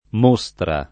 [ m 1S tra ]